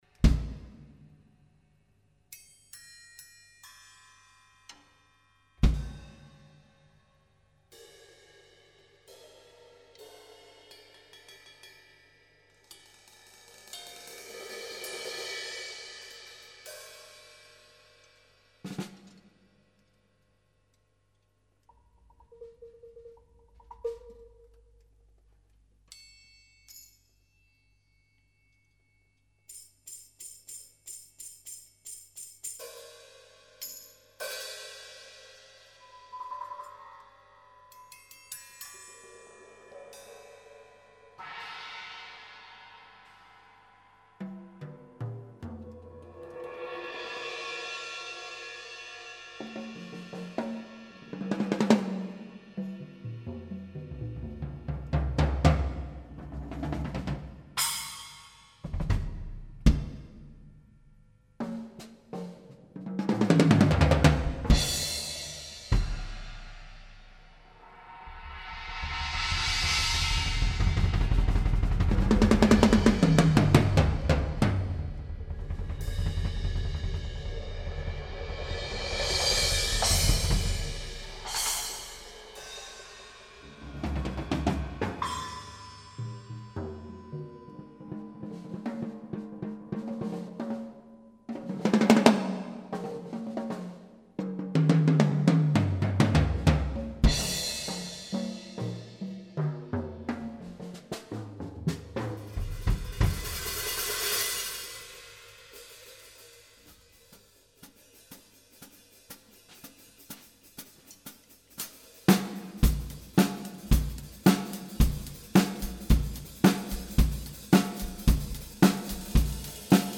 Auf der Suche nach der Eins, letzten Samstag in Rödelheim: gelb gegen rot Wer mehr links und wer mehr rechts ist, darf ich net saggsche.
Trommeln: Tama, Sonor, Wahan, Pearl Becken: Meinl Candala, Raker; Paiste 2002, 505, Signature, Sound Creation Aufgenommen haben wir mit zwei gekoppelten Zoom R 16